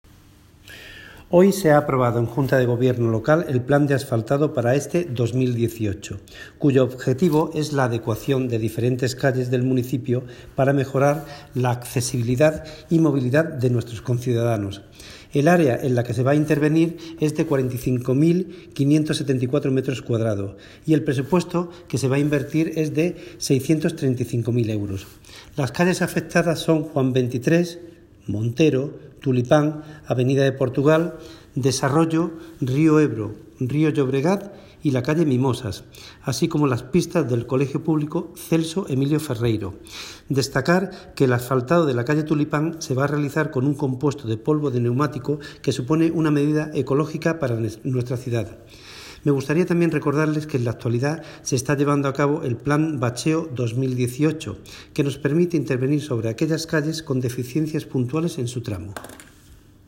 Audio - Agustín Martín (Concejal de Deportes, Obras, Infraestructuras y Mantenimiento de vías públicas) Sobre Asfaltado